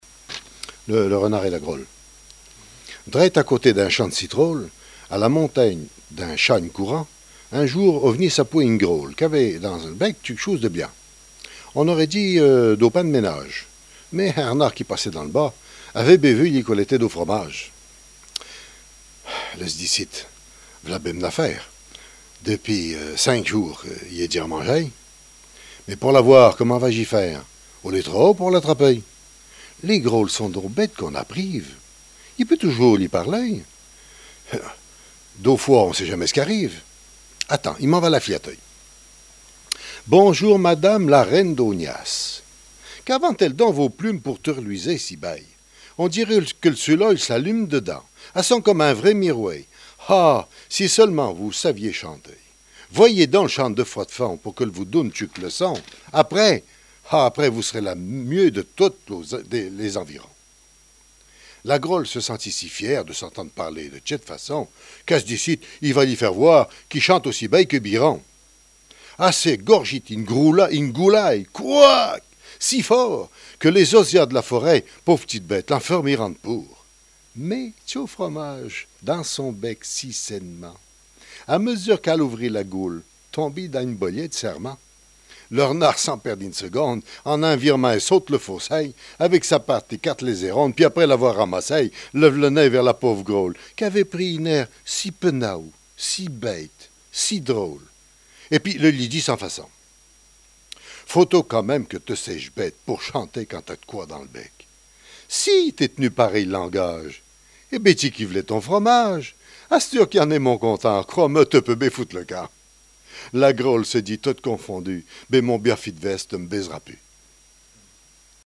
Langue Maraîchin
Fables chantées
Genre fable